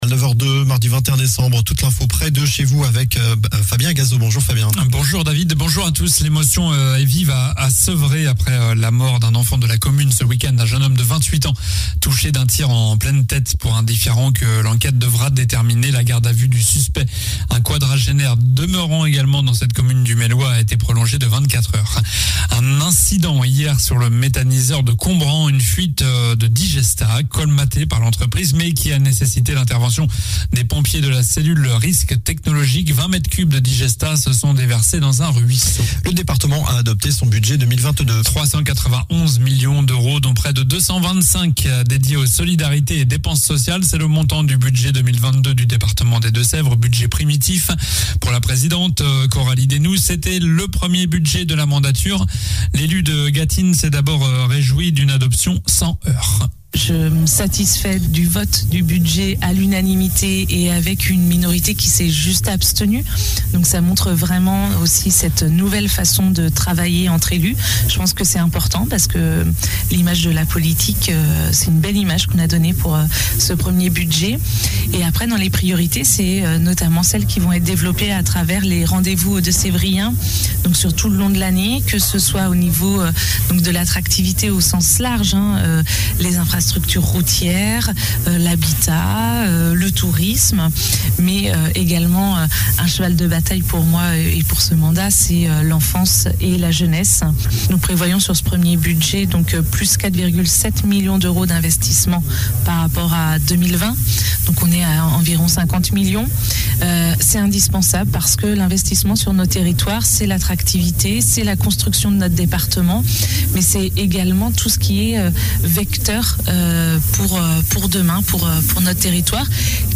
Journal du mardi 21 décembre (matin)